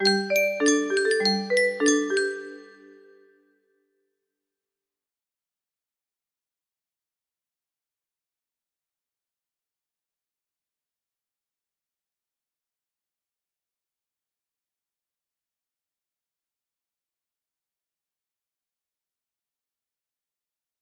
Mema's Song music box melody